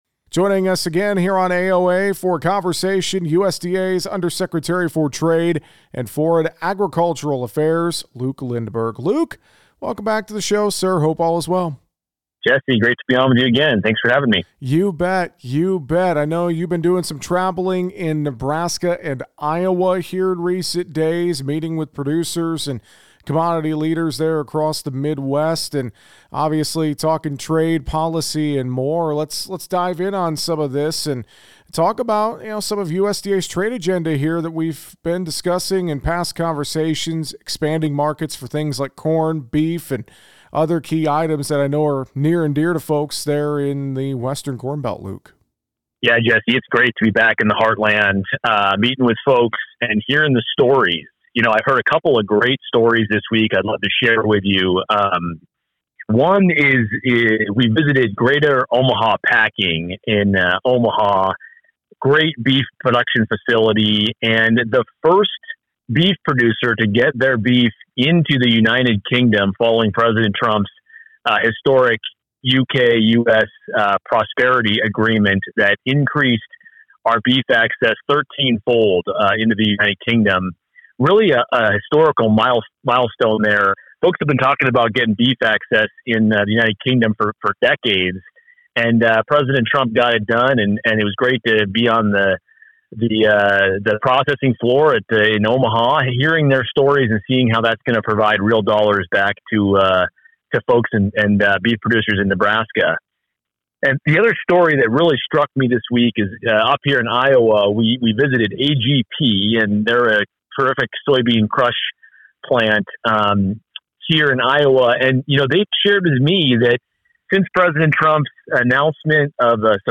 USDA Undersecretary for Trade and Foreign Agriculture Affairs Luke Lindberg sits down with us for an update on USDA’s trade agenda, some on the ground perspective from his travels to Iowa and Nebraska in the last few days and thoughts on his nomination by the United States to serve as Executive Director of the UN World Food Programme.